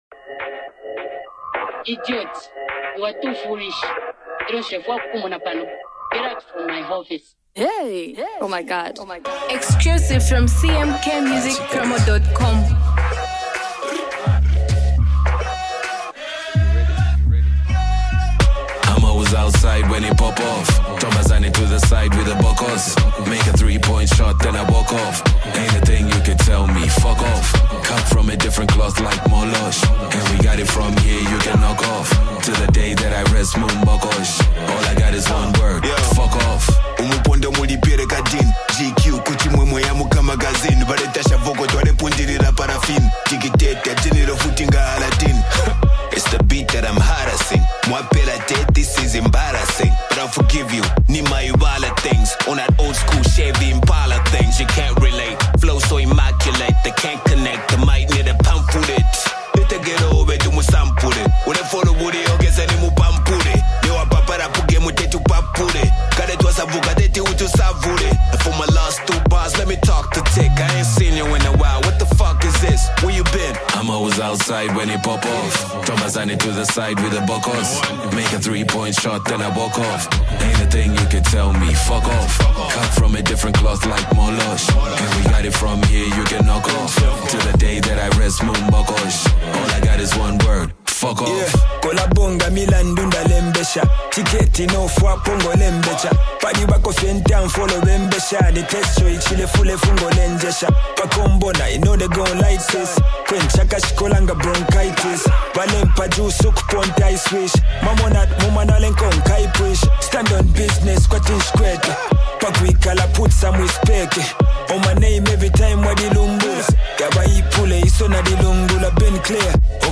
HIPHOP jam